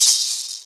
DDWV SHAKE 1.wav